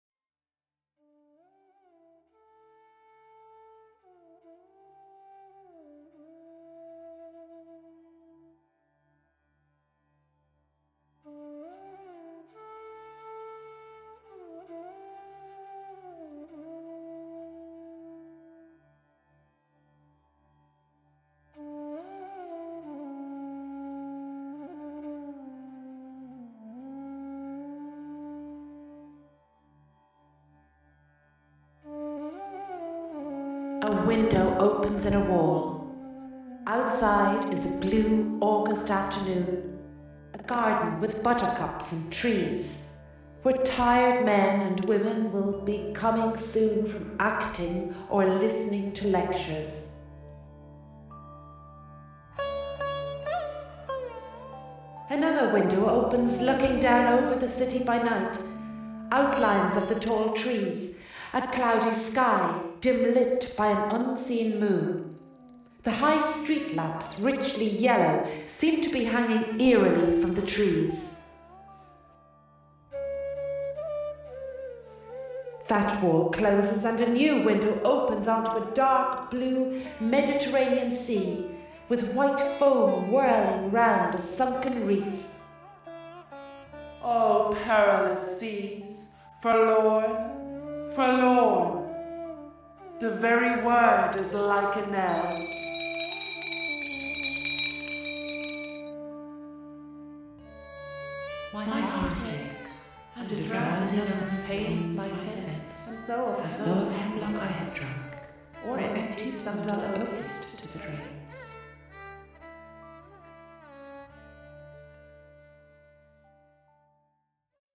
Soundscapes